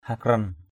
/ha-krʌn/ (t.) kéo giãn = tirer pour allonger. balan hakran blN hkN tháng nhuận = mois intercalaire.
hakran.mp3